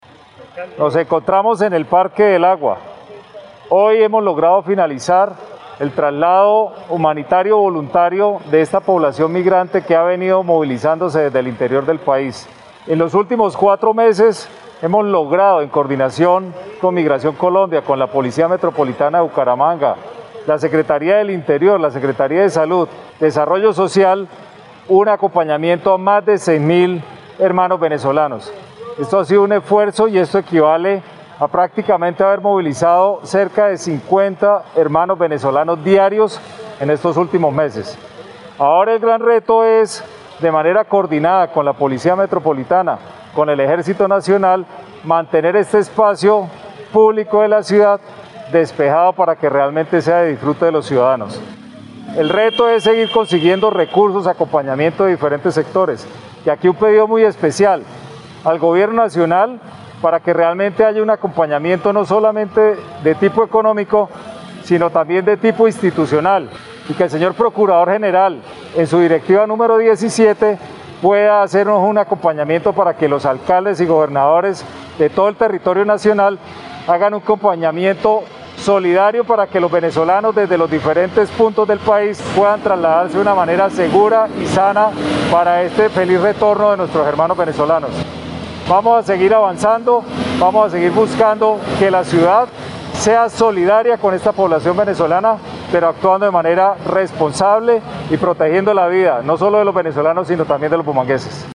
Descargar audio: Juan Carlos Cárdenas, alcalde de Bucaramanga